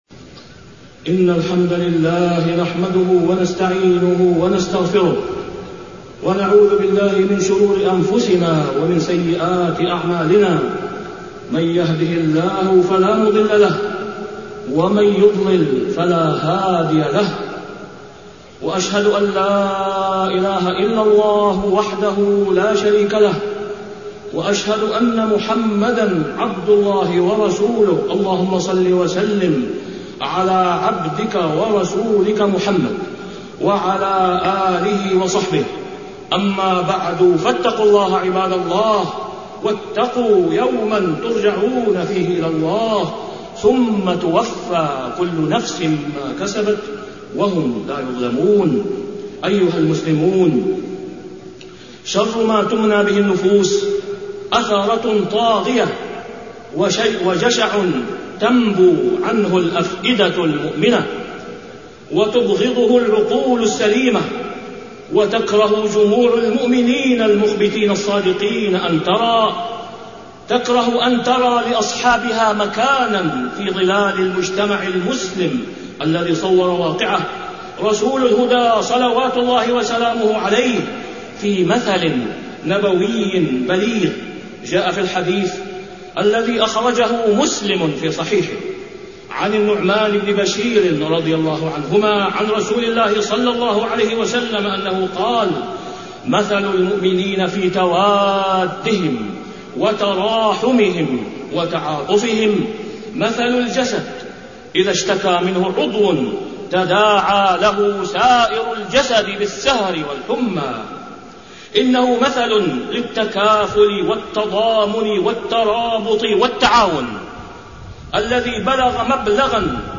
تاريخ النشر ١٥ صفر ١٤٢٩ هـ المكان: المسجد الحرام الشيخ: فضيلة الشيخ د. أسامة بن عبدالله خياط فضيلة الشيخ د. أسامة بن عبدالله خياط جشع الأموال ورفع الأسعار